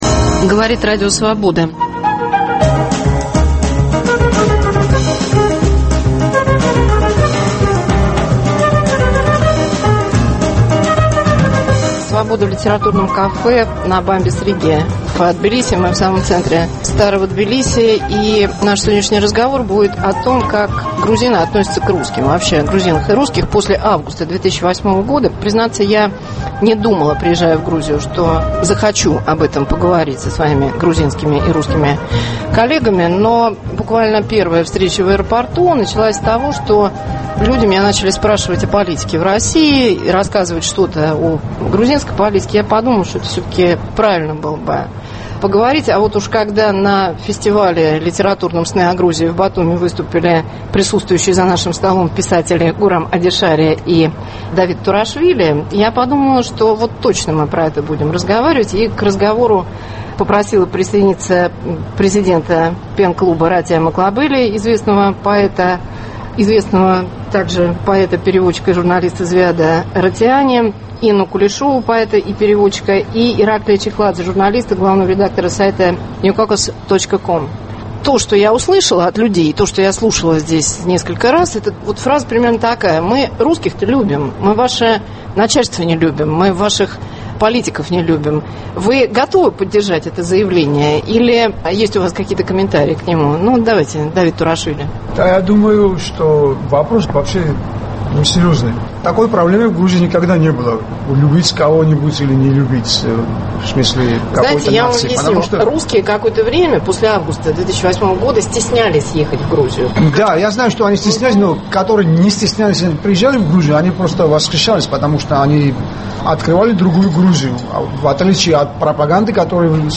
Свобода в литературном кафе на Бамбис риги, Тбилиси. Грузины и русские после августа 2008.